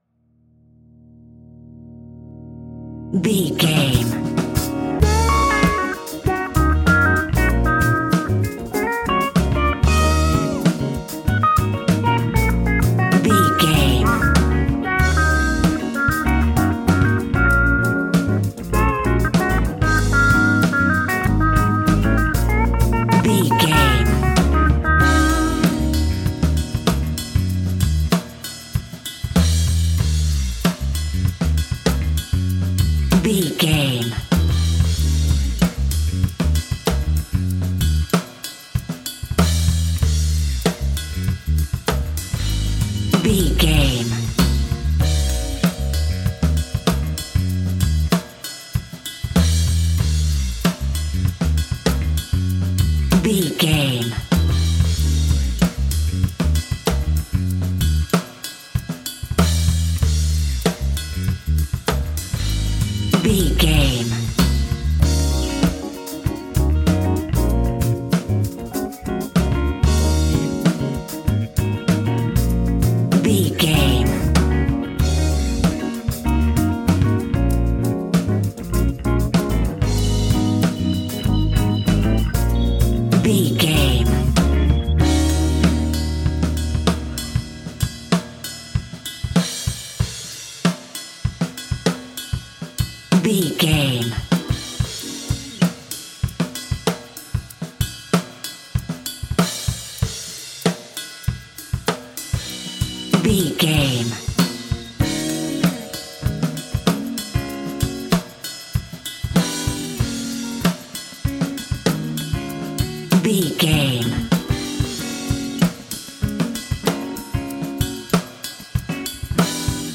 Fast paced
In-crescendo
Uplifting
Ionian/Major
D♯